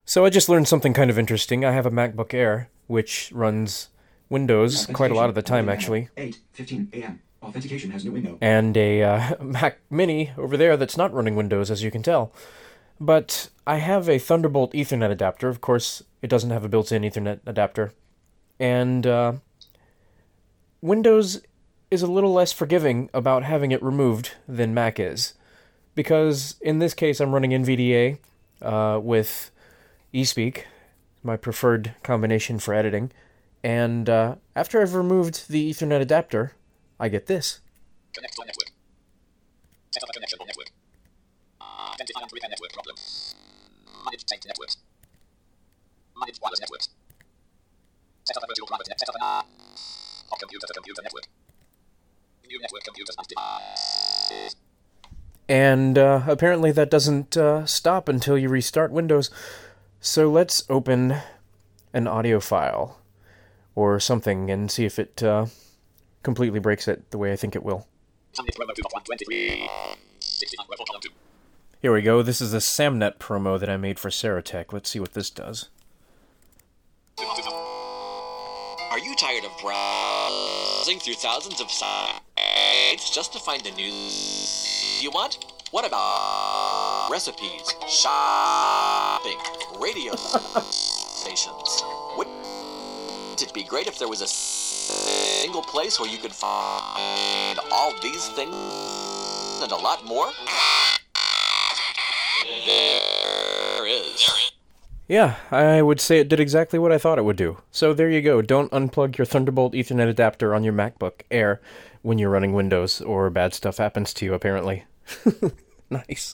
This is what happens to audio on my Macbook Air running Windows when the Apple thunderbolt adapter is removed.